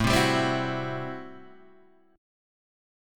A Minor 13th
Am13 chord {5 3 5 5 5 2} chord